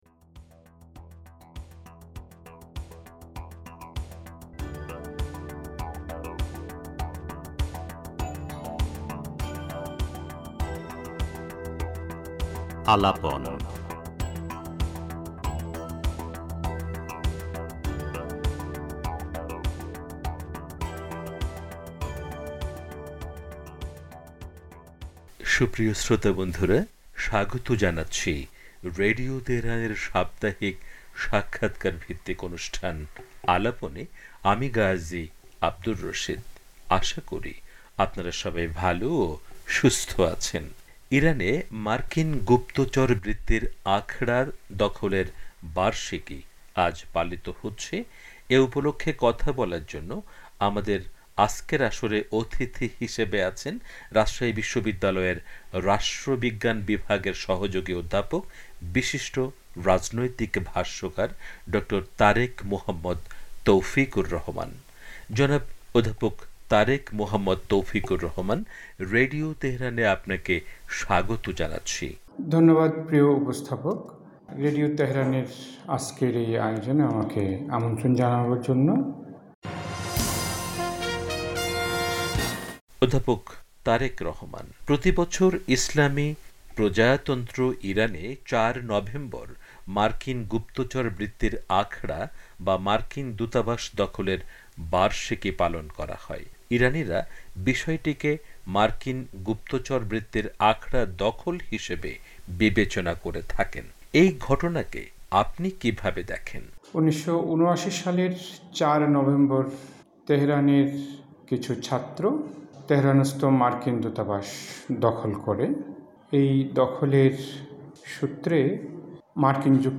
তবে আমেরিকার সেইসব নিষেধাজ্ঞাকে মোকাবেলা করে ইরান টিকে আছে আত্মমর্যাদা নিয়ে। পুরো সাক্ষাৎকারটি তুলে ধরা হলো।